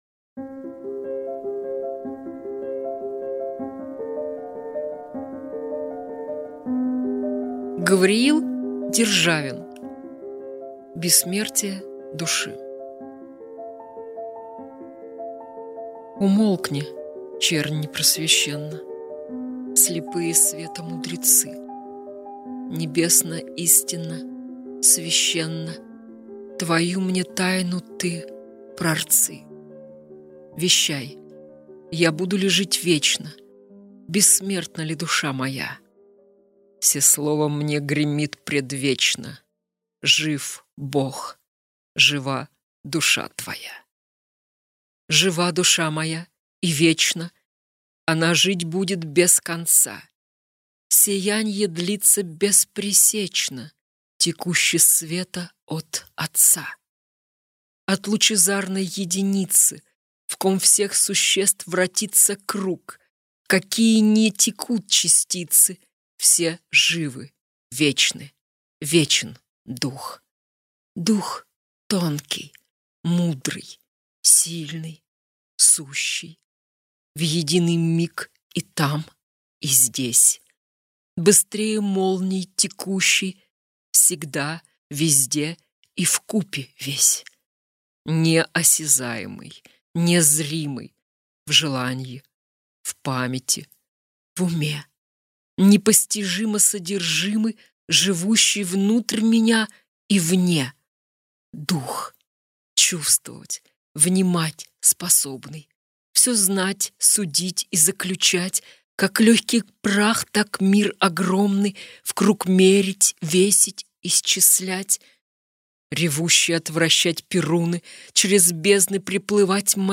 Logos-Literaturnye-chteniya.-Gavriil-Romanovich-Derzhavin-Bessmertie-dushi-stih-club-ru.mp3